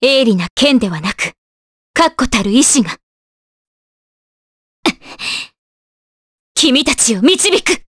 Scarlet-vox-get_Jp.wav